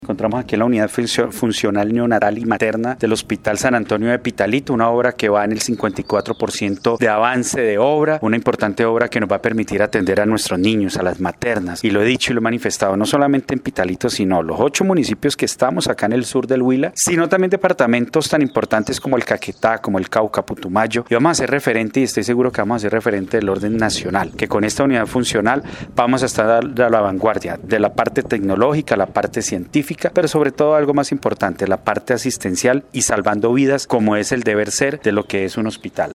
Audio: Los detalles nos los comparte el secretario de Salud departamental, Sergio Mauricio Zuñiga